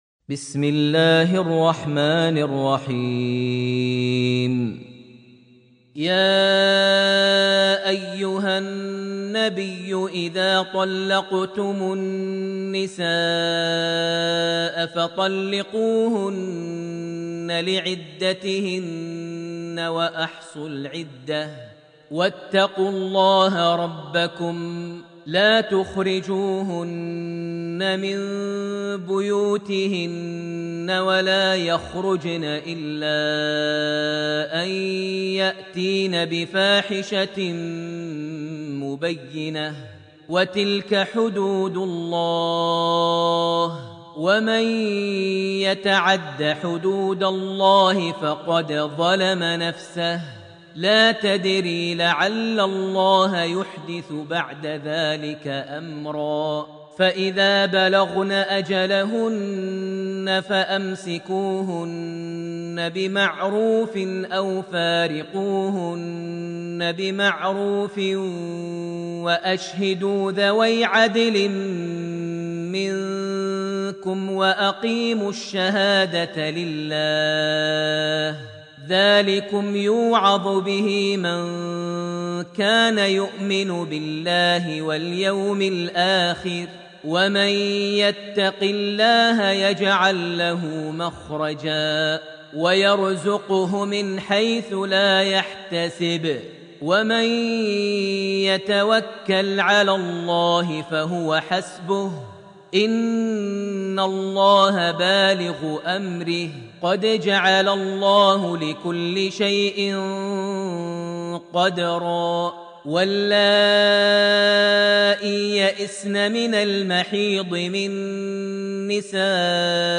Surah Al-Talaq > Almushaf > Mushaf - Maher Almuaiqly Recitations